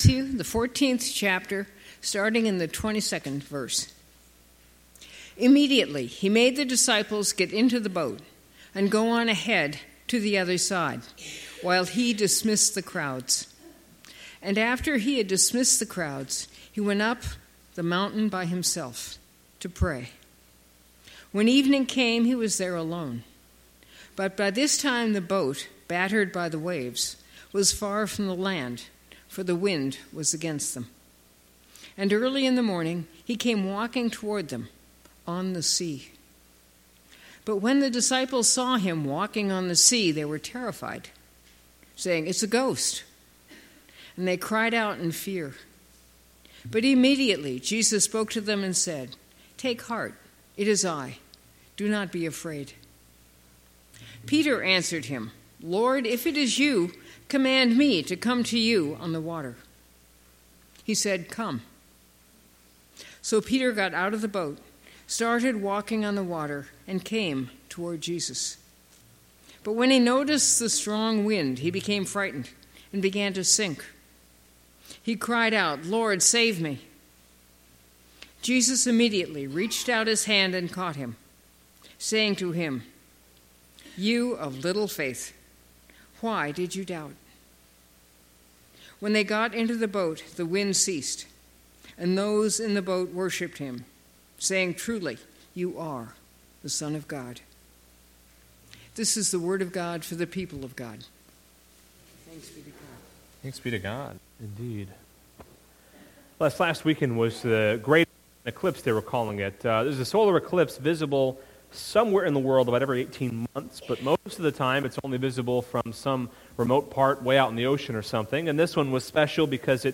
STEPPING OUT Passage: Matthew 14:22-33 Service Type: Sunday Morning Topics